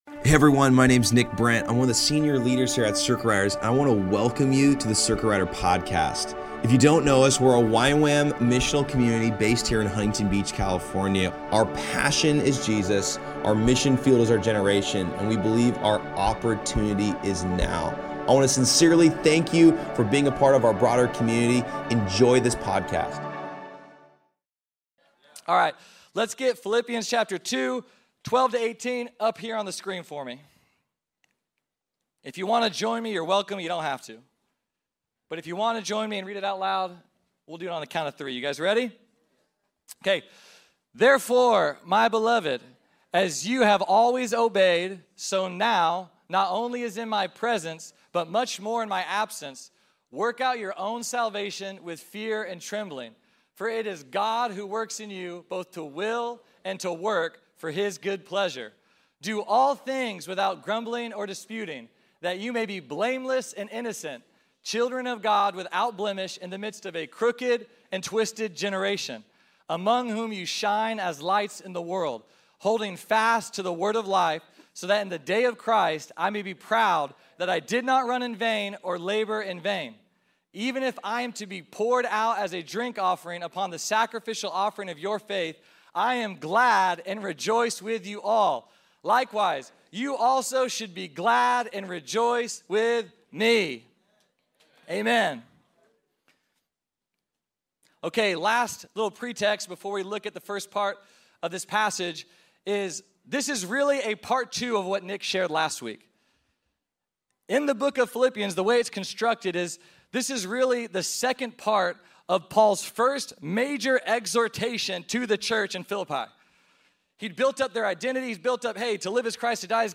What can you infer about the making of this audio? At Circuit Riders Monday Night on April 29th 2024